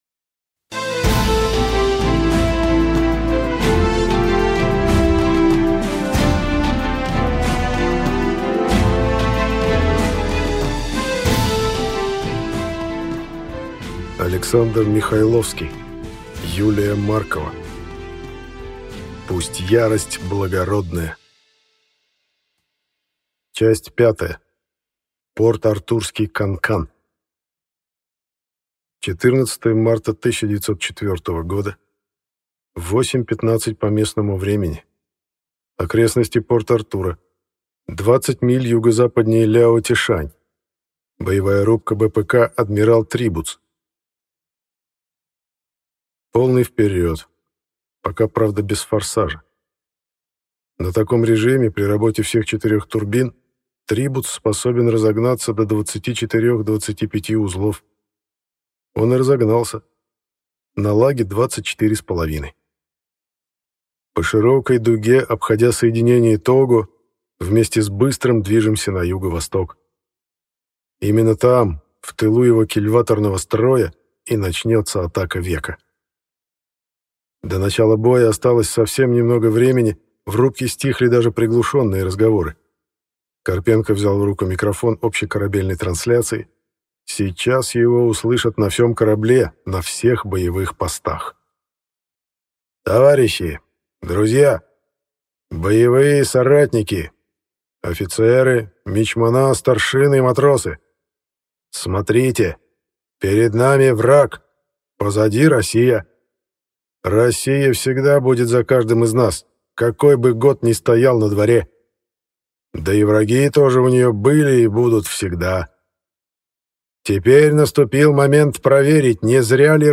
Аудиокнига Пусть ярость благородная | Библиотека аудиокниг
Прослушать и бесплатно скачать фрагмент аудиокниги